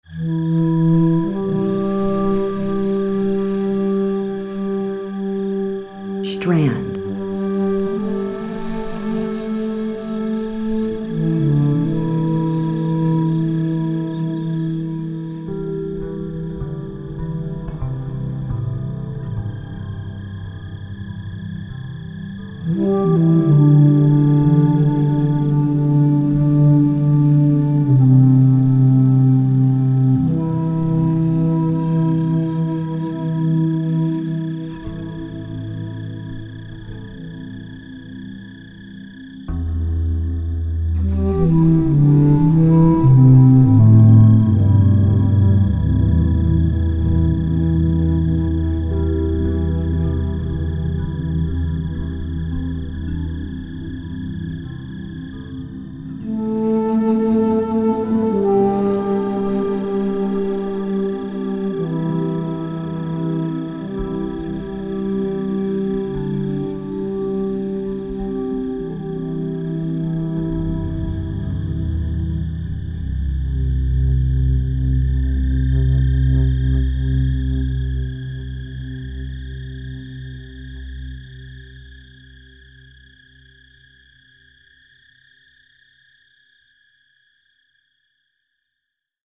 Relaxace, Meditace, Relaxační a Meditační hudba